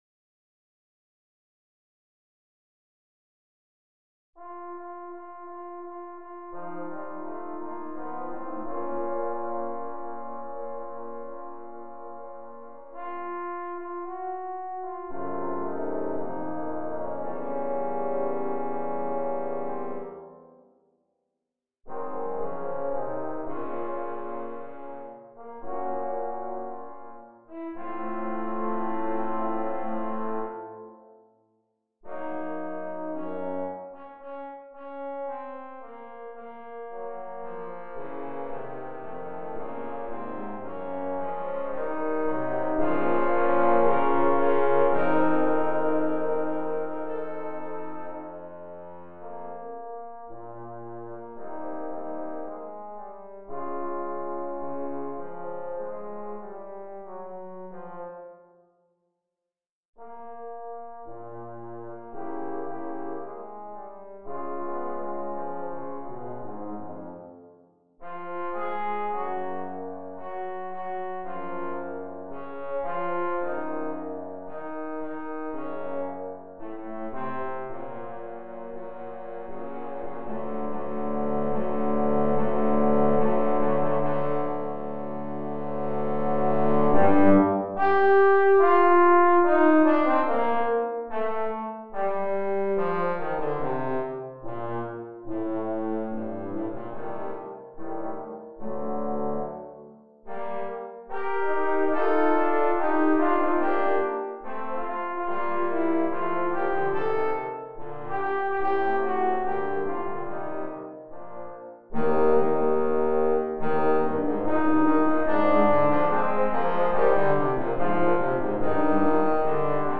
Trombone Quartet Edition
3 tenor + 1 bass